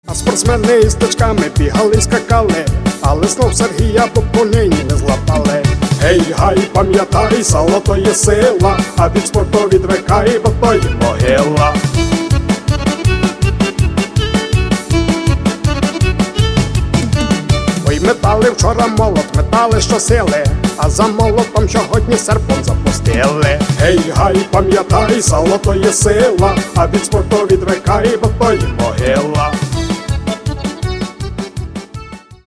Каталог -> MP3-CD -> Народная